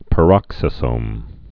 (pə-rŏksĭ-sōm)